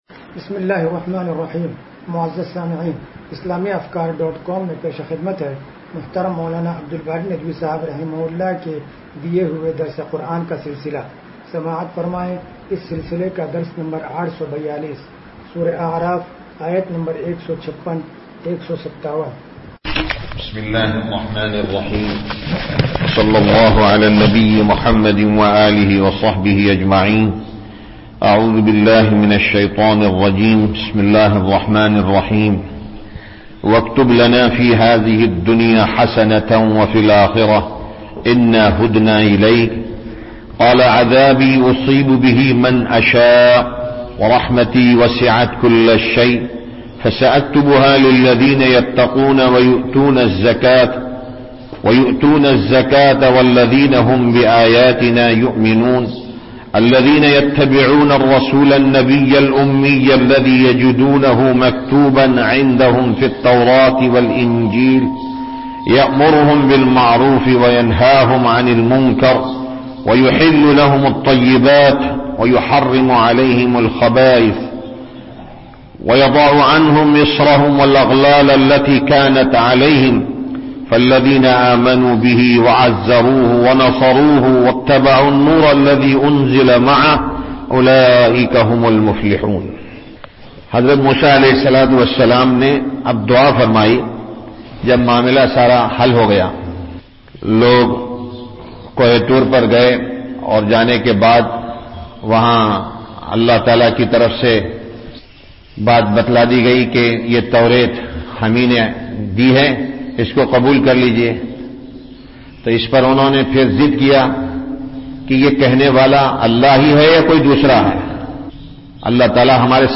درس قرآن نمبر 0842
درس-قرآن-نمبر-0842.mp3